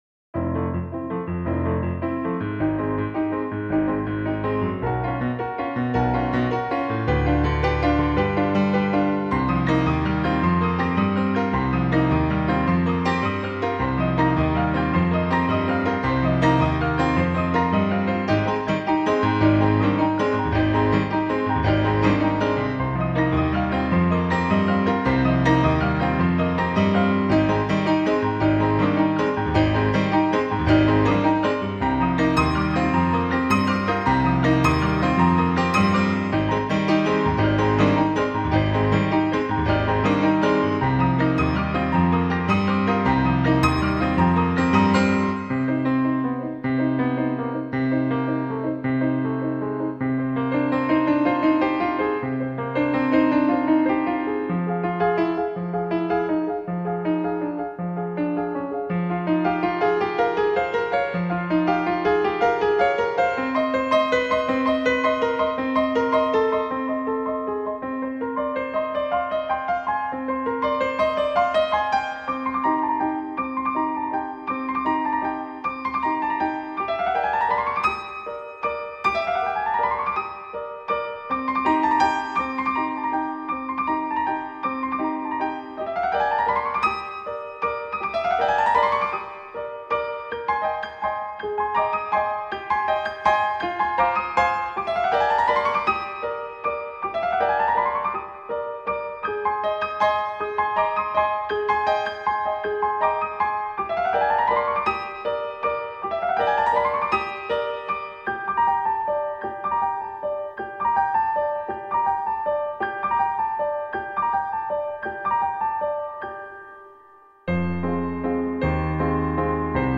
A secret garden of lucid musical dreams .
Tagged as: New Age, Instrumental New Age, Contemporary Piano